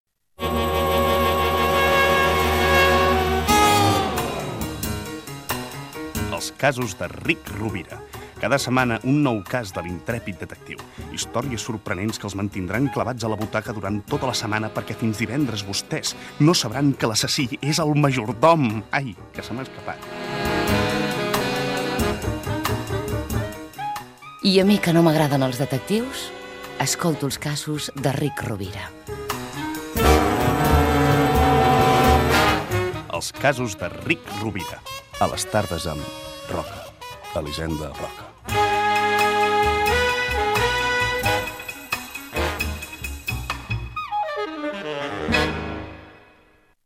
Promoció de la ficció "Els casos de Rick Rovira"
Fragment extret de l'arxiu sonor de COM Ràdio